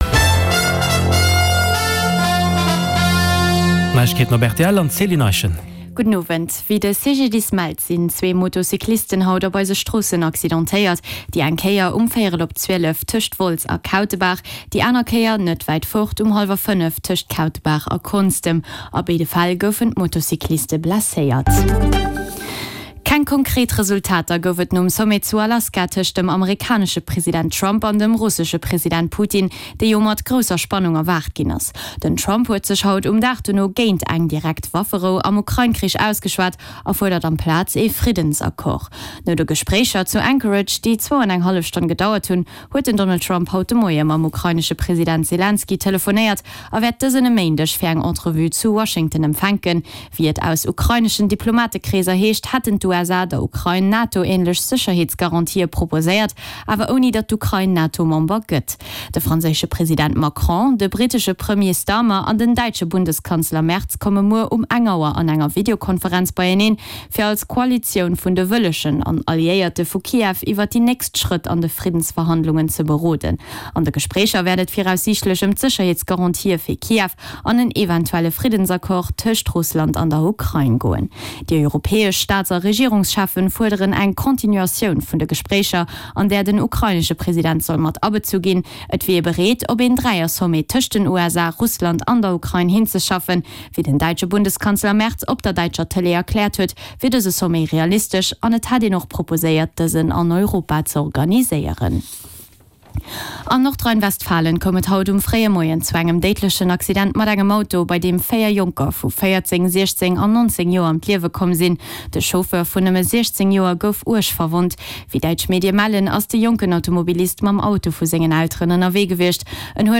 Play Rate Listened List Bookmark Get this podcast via API From The Podcast Den News Bulletin mat allen Headlines aus Politik, Gesellschaft, Economie, Kultur a Sport, national an international Join Podchaser to...